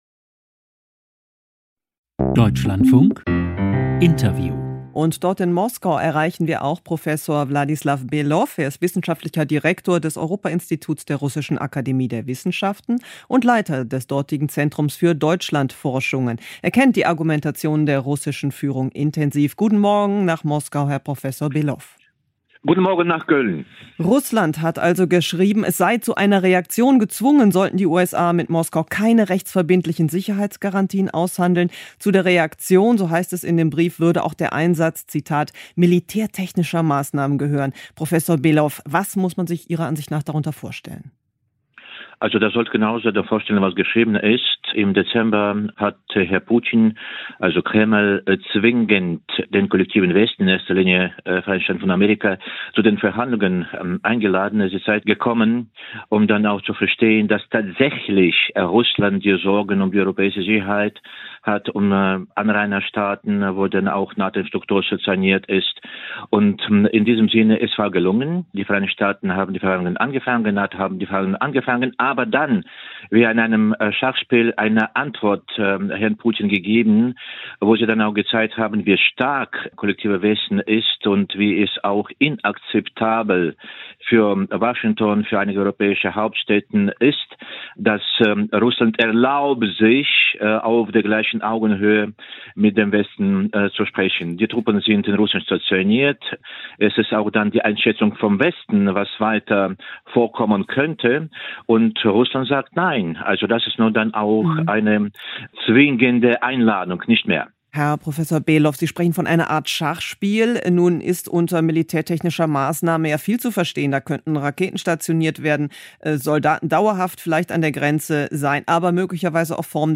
Dr. Christoph Heusgen, neuer Chef Münchner Sicherheitskonferenz – Der Mann mit der diplomatischen Abrissbirne – im Interview des Dlf am 17.2.2022